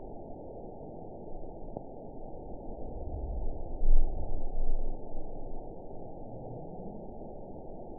event 910862 date 02/01/22 time 08:50:06 GMT (3 years, 3 months ago) score 8.39 location TSS-AB01 detected by nrw target species NRW annotations +NRW Spectrogram: Frequency (kHz) vs. Time (s) audio not available .wav